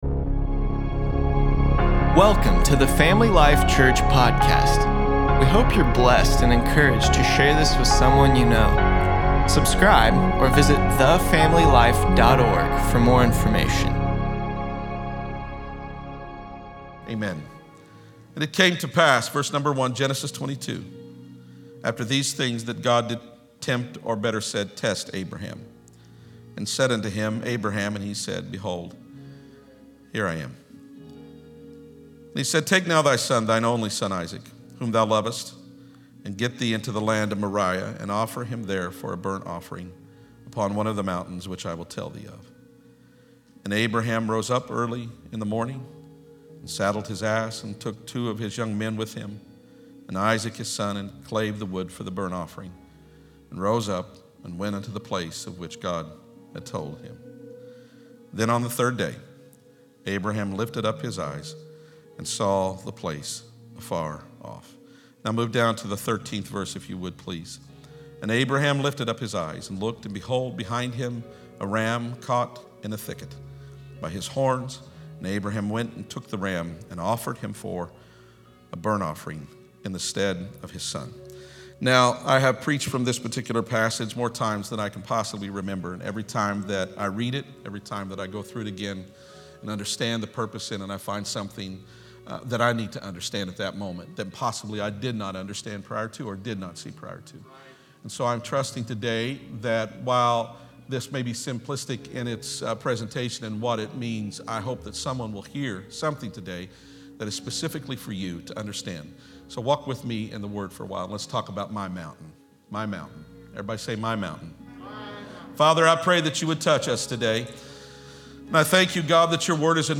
4.18.21_sermon.mp3